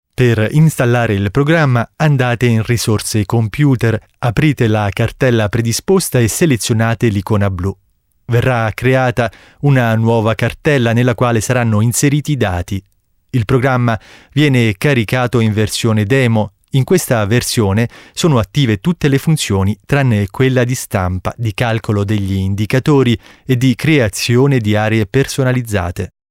Sprecher italienisch.
Kein Dialekt
Sprechprobe: Industrie (Muttersprache):